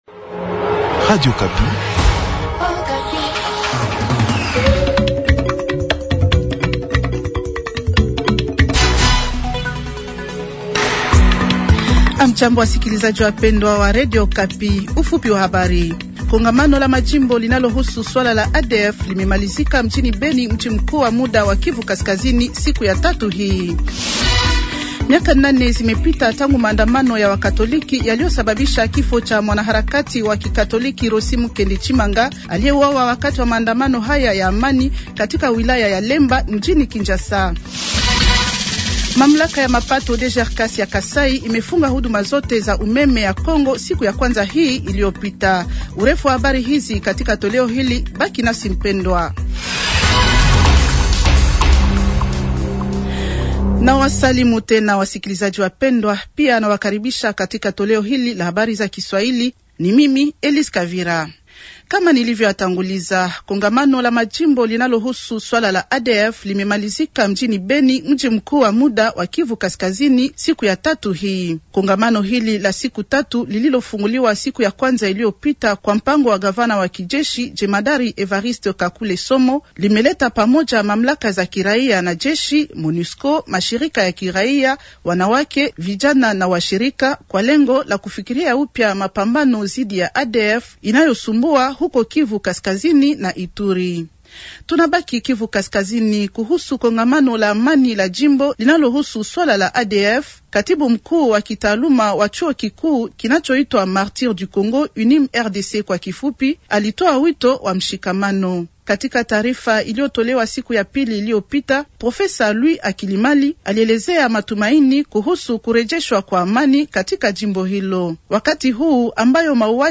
Journal Swahili de mercredi soir 250226